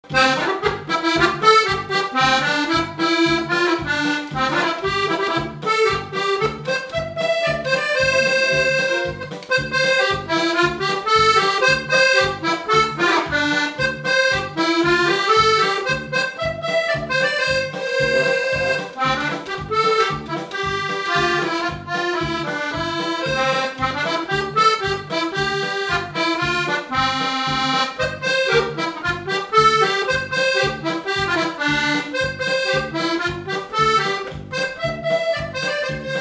Avant-deux
Résumé instrumental Usage d'après l'analyste gestuel : danse
Pièce musicale inédite